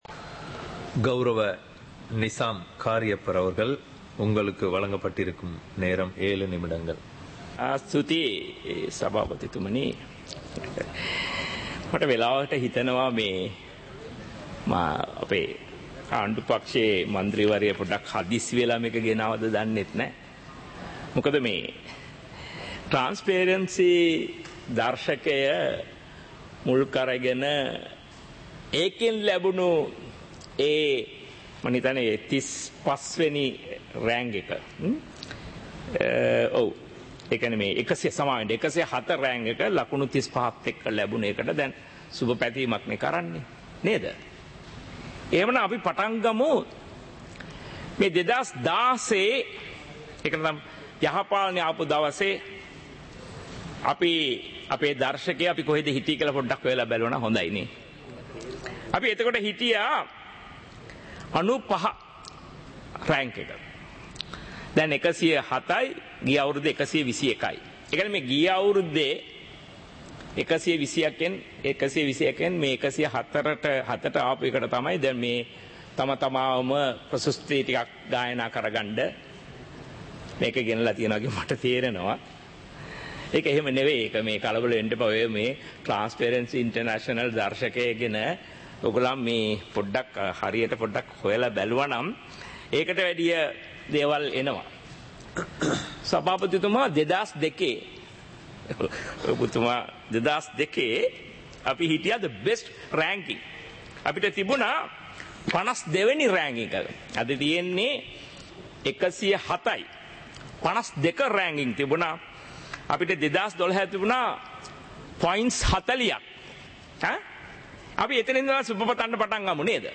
சபை நடவடிக்கைமுறை (2026-02-18)